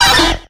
Audio / SE / Cries / POLIWHIRL.ogg